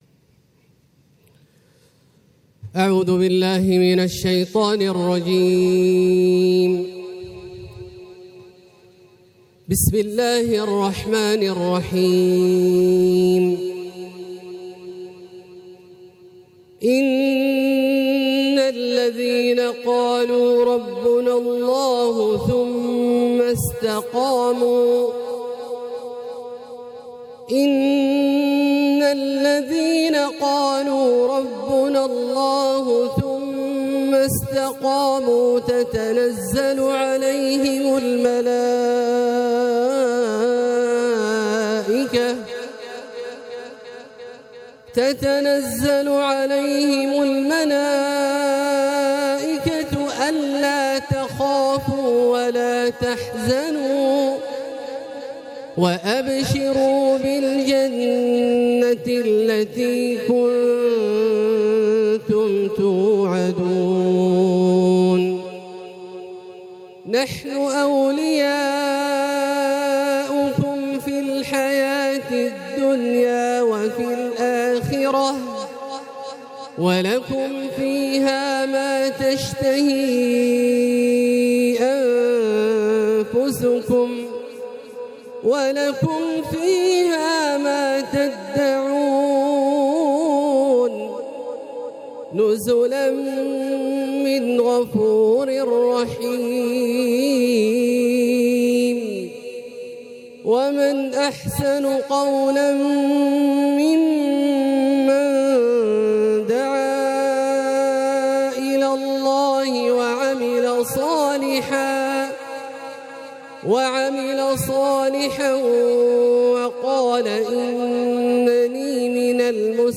تلاوة حبّرها د. عبدالله الجهني تحبير أخّاذ من سورة فصلت | حفل رابطة العالم الإسلامي منهاج 5-6-1447هـ > مشاركات الشيخ عبدالله الجهني في اجتماعات الرئاسة > المزيد - تلاوات عبدالله الجهني